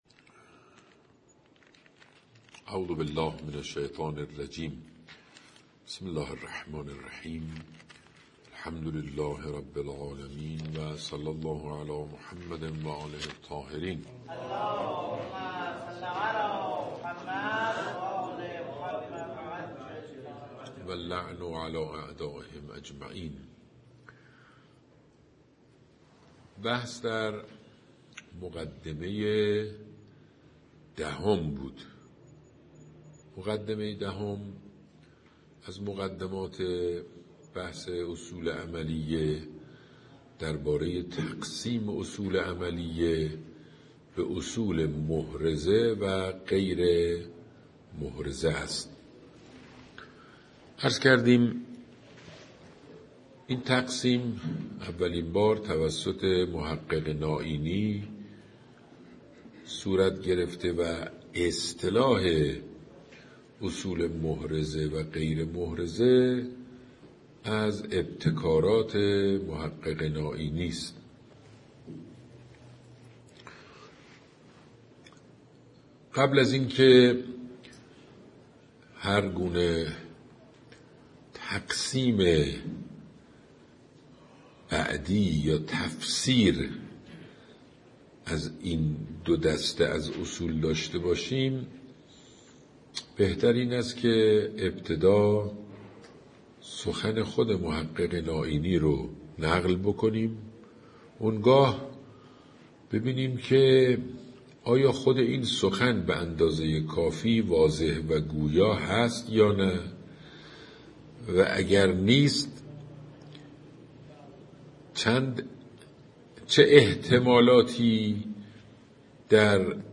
درس اصول: بررسی تقسیم‌بندی اصول عملیه به محرزه و غیرمحرزه و تحلیل ویژگی‌های علم تکوینی از دیدگاه محقق نائینی.